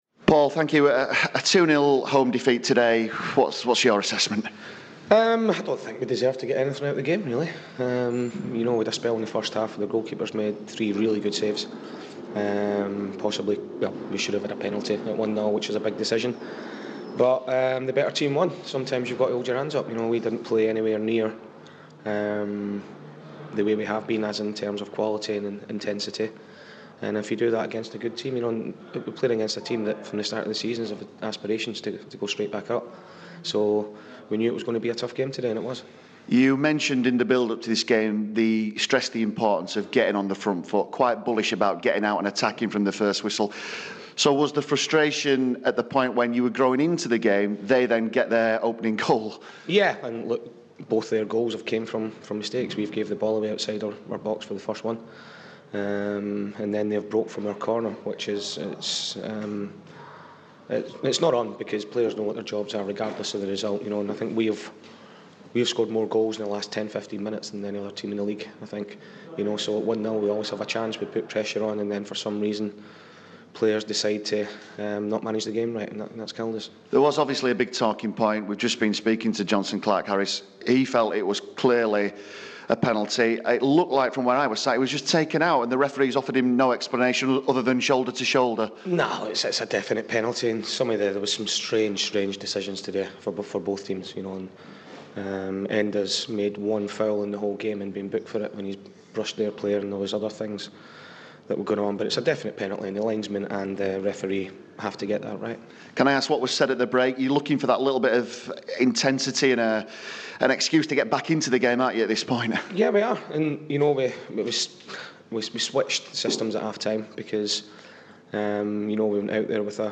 INTERVIEW: Doncaster boss Paul Dickov after Rovers 2-0 loss to Peterborough